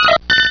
Cri de Togepi dans Pokémon Rubis et Saphir.